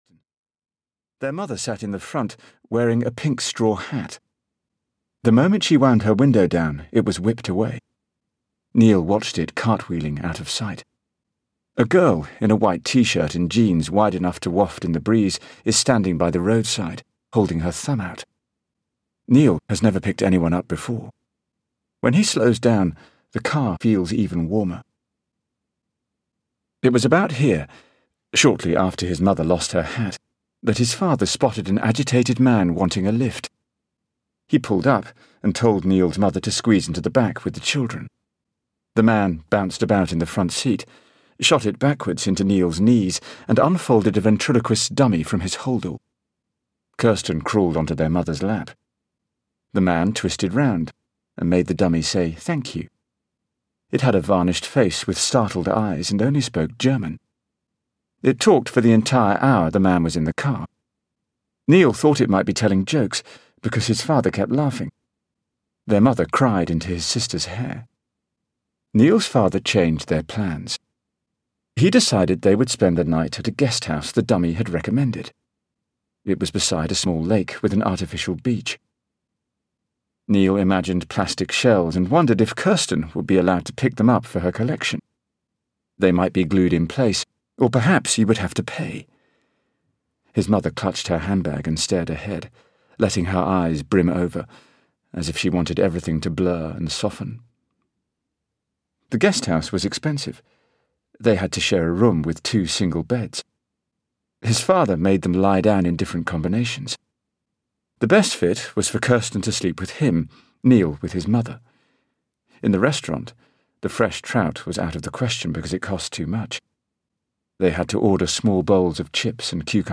Audiobook Instructions for the Working Day written by Joanna Campbell.
Ukázka z knihy